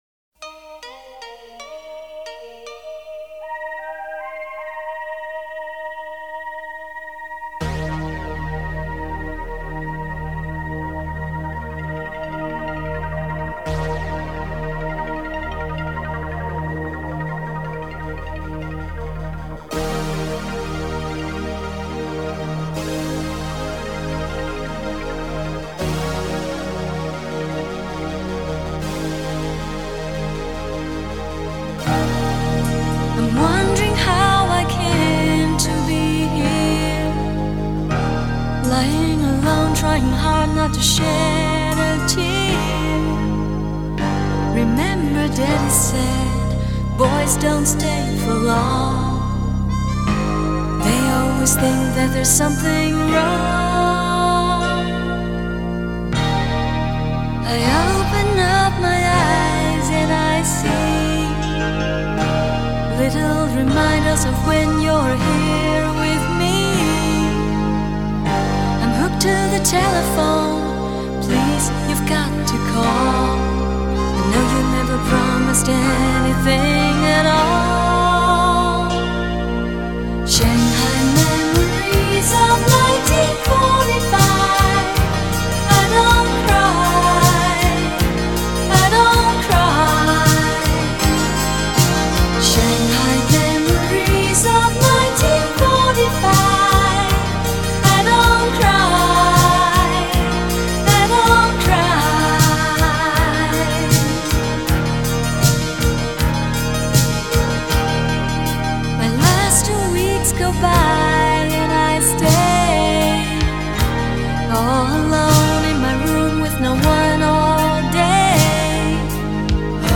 Жанр: Chinese pop / Pop